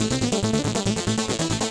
Index of /musicradar/8-bit-bonanza-samples/FM Arp Loops
CS_FMArp B_140-A.wav